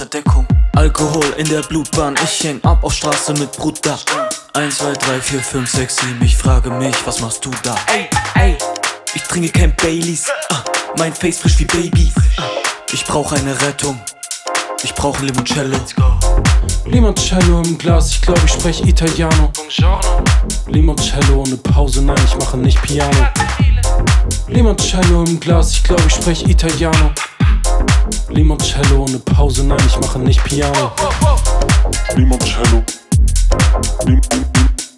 Жанр: Танцевальные / Хаус